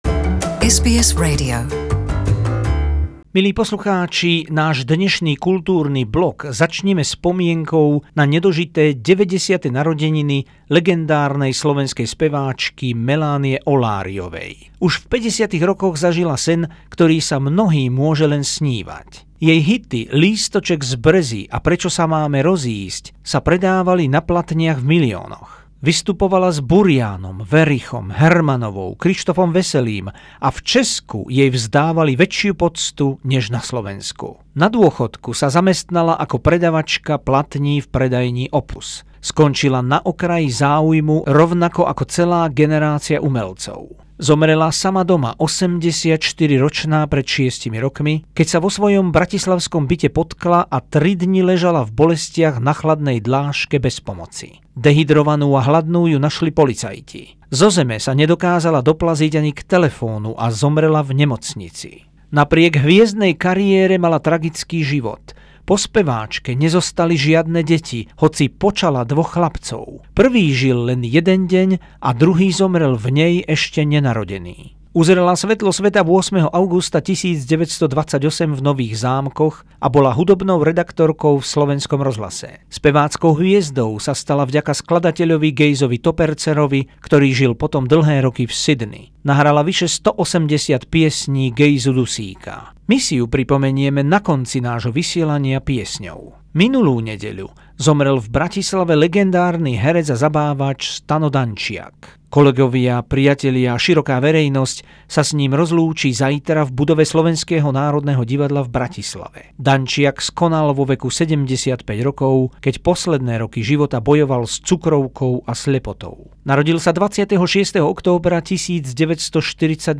Spomíname na speváčku Melániu Olláryovú k jej nedožitým 90. narodeninám, na herca Stana Dančiaka, ktorý nás opustil vo veku 75 rokov a legendárneho hokejistu Stanislava Gôta Mikitu, ktorý zomrel v Kanade vo veku 78 rokov. V závere zaznie aj jeho vzácna, už trochu lámaná slovenčina, na ktorú bol hrdý.